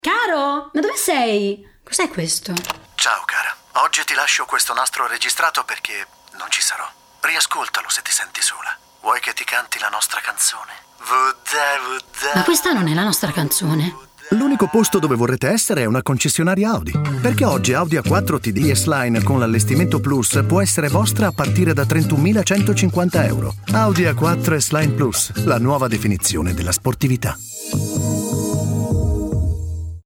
attore doppiatore